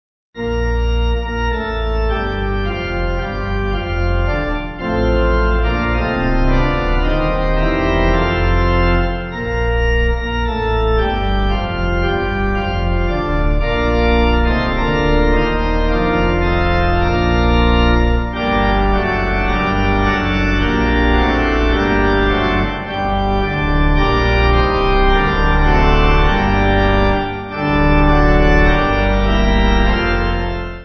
8.7.8.7.D
Organ